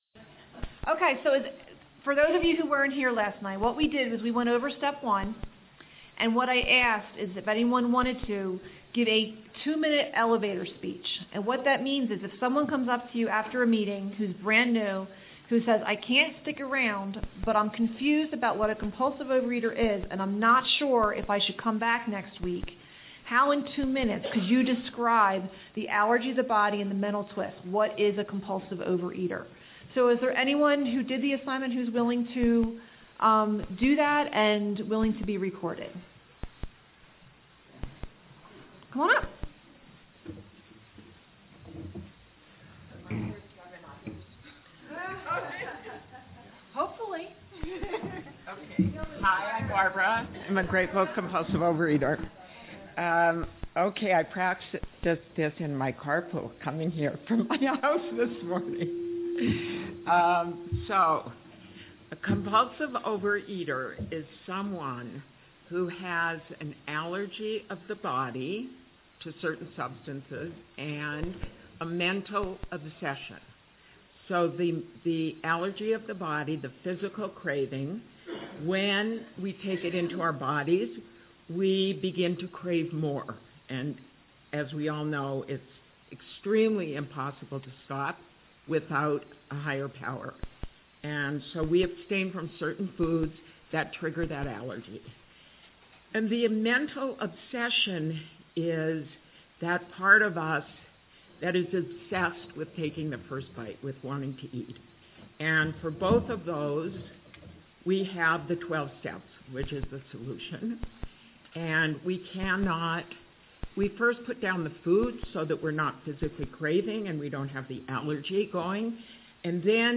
South Broadway Christian Church
A Big Book Retreat in Estes Park